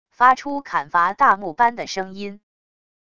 发出砍伐大木般的声音wav音频